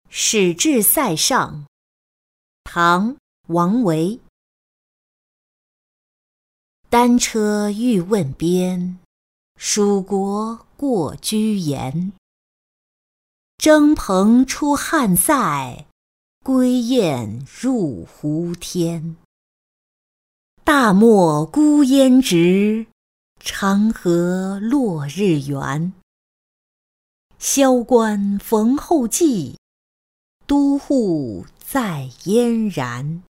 使至塞上-音频朗读